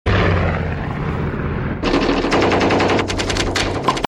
MACHINE GUN FIRE WITH PLANE.mp3
Original creative-commons licensed sounds for DJ's and music producers, recorded with high quality studio microphones.
machine_gun_fire_with_plane_638.ogg